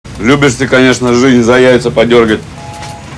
• Качество: 128, Stereo
нецензурная лексика
голосовые